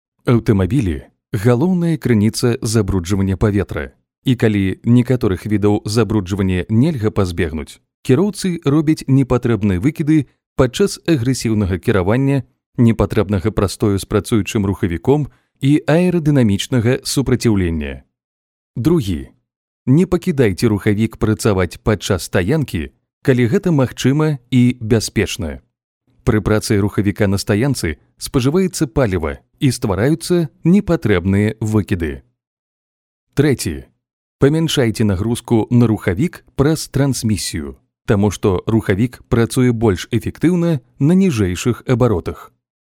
Belarusca Seslendirme
Erkek Ses